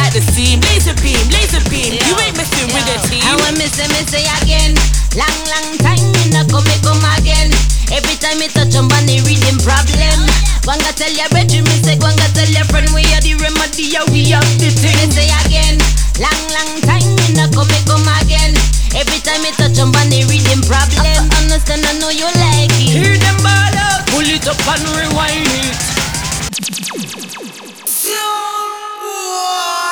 Genre: Jungle/Drum'n'bass